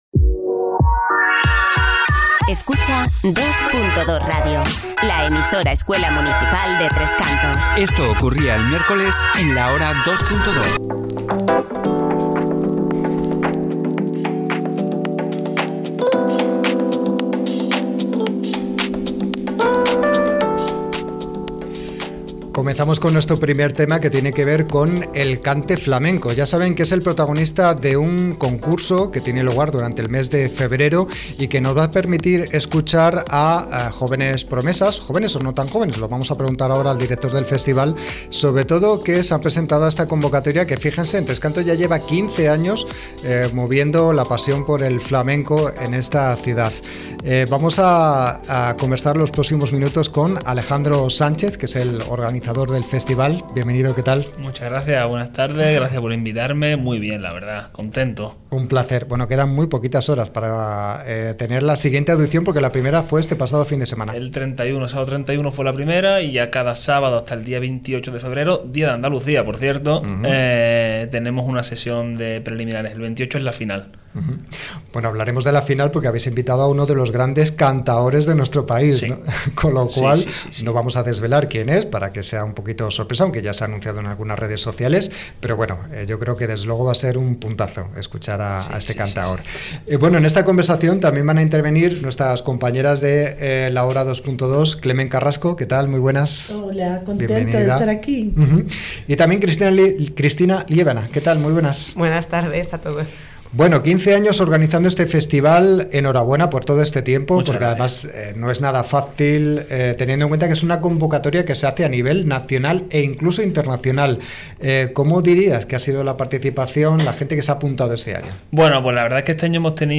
ENTREVISTA-CONCURSO-CANTEFLAMENCO.mp3